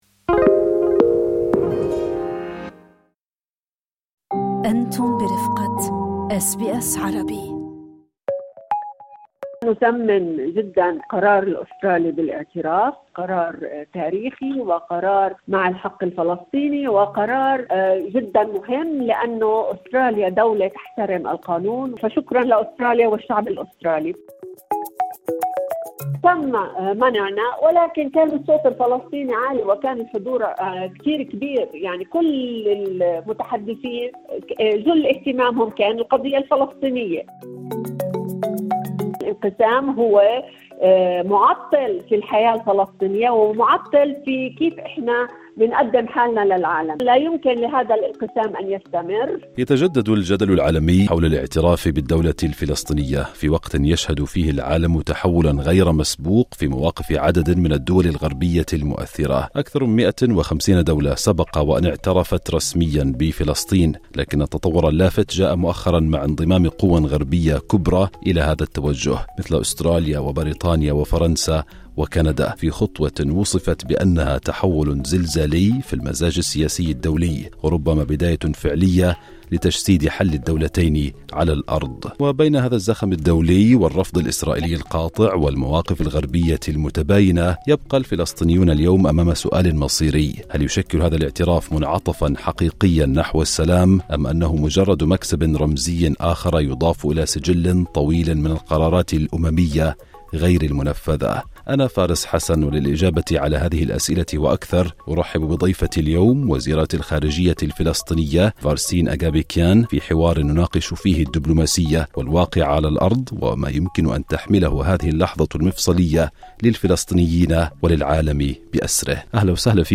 لقاء خاص مع وزيرة الخارجية الفلسطينية، فارسين أغابكيان، تطرقت فيه للموقف الفلسطيني إزاء اعتراف أستراليا بالدولة الفلسطينية، وتحدثت أيضاً عن الخطوات المقبلة وما إذا كان حل الدولتين لا يزال ممكناً.